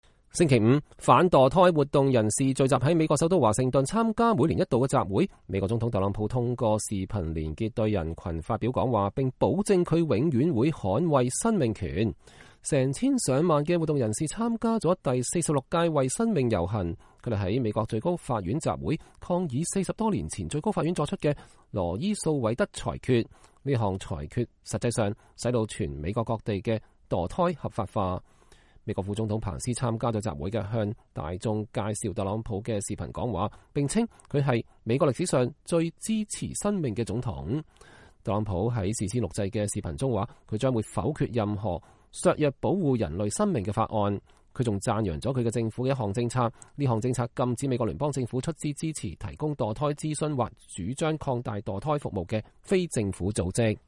反墮胎人士華盛頓年度集會 總統視頻講話 副總統到場發言
美國副總統彭斯參加了集會，向人們介紹特朗普的視頻講話，並稱他是“美國歷史上最支持生命的總統”。